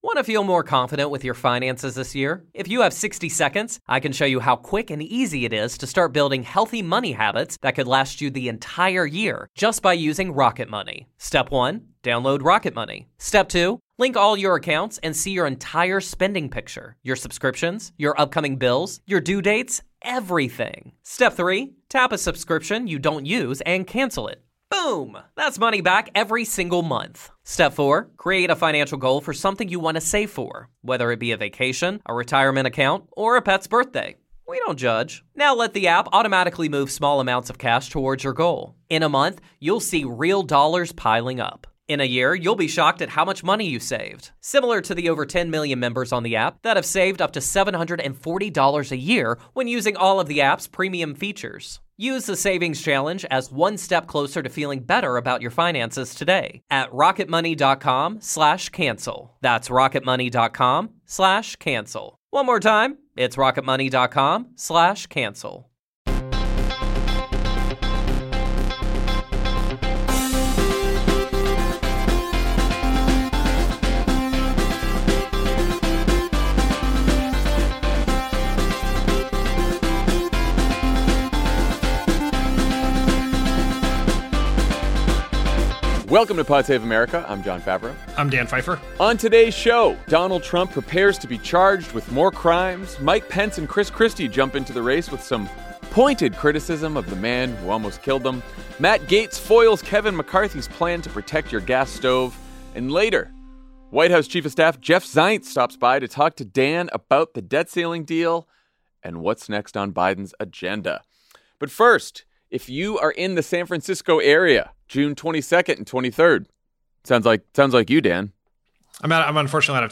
And later, White House Chief of Staff Jeff Zients stops by to talk about the debt ceiling deal and what’s next on Biden’s agenda.